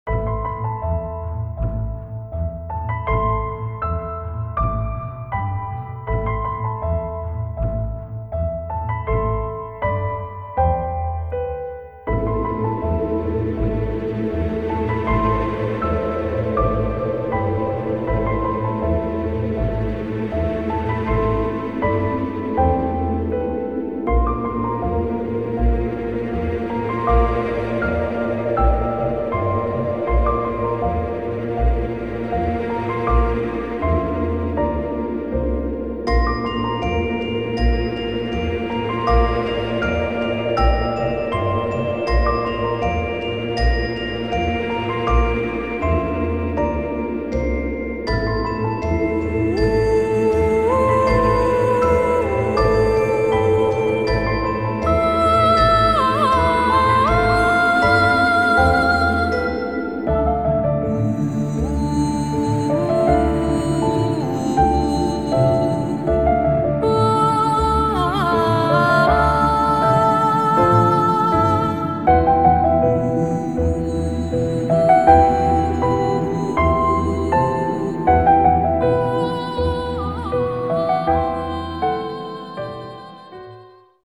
ΟΡΧΗΣΤΡΙΚΑ